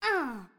SFX_Mavka_Hit_Voice_10.wav